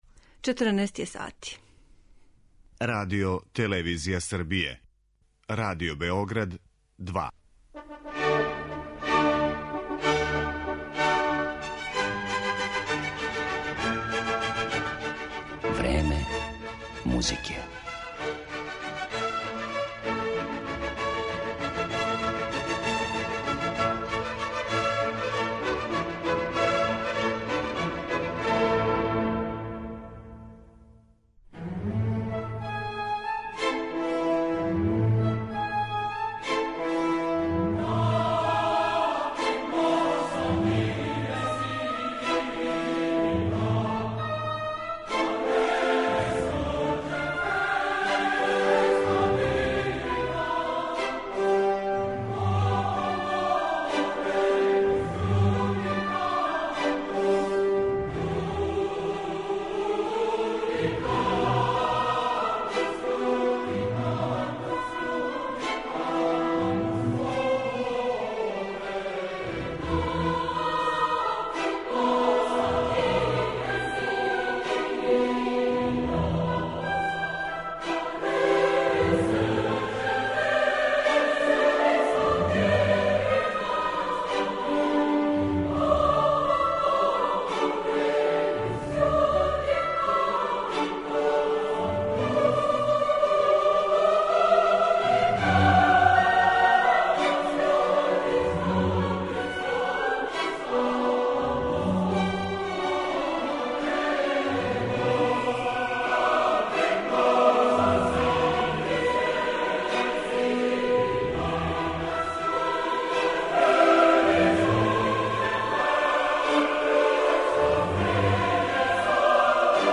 У извођењу реномираних светских оркестара и диригената, слушаћете фрагменте из Фантастичне симфоније, Харолда у Италији , драмске симфоније Ромео и Јулија, Реквијема и других композиција овог творца првих дела из области програмске музике. Одликује их врхунско познавање оркестрације, употреба моћног извођачког апарата и проширење форме до монументалних димензија.